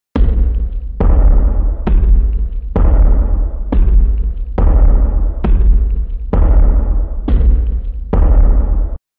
pasos_2.mp3